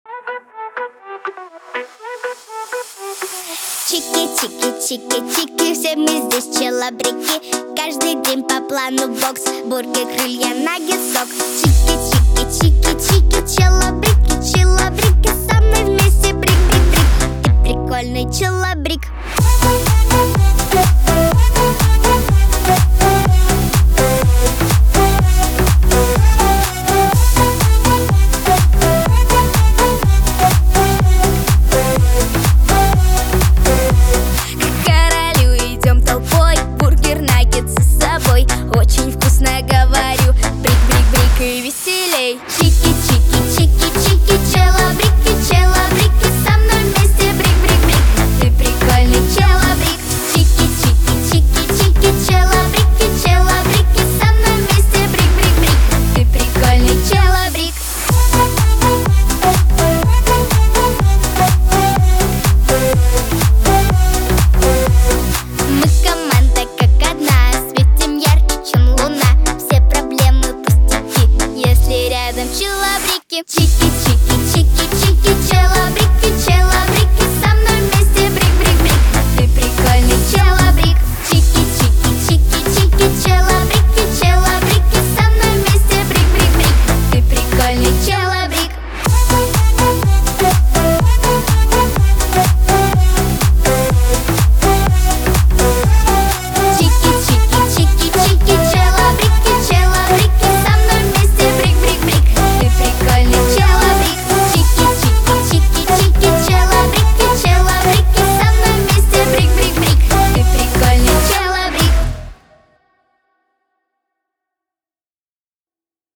Лирика
дуэт , Веселая музыка